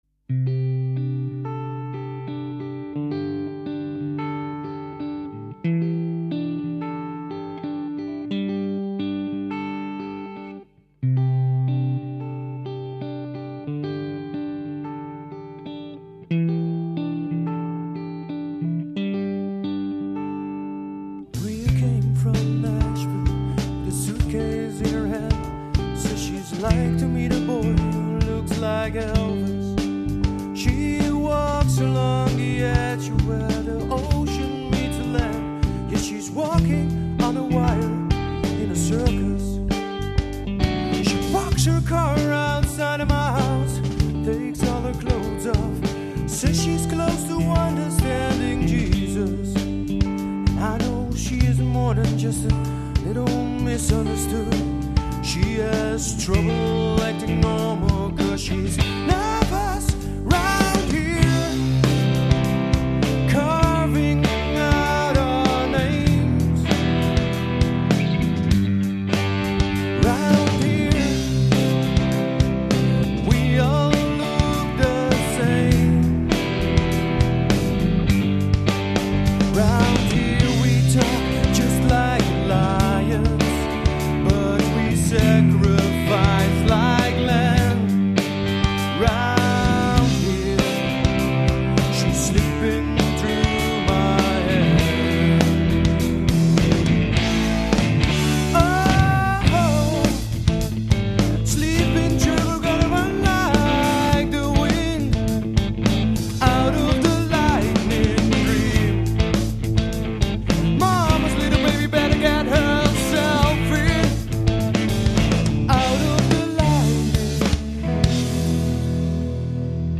Zang
Gitaar
Drums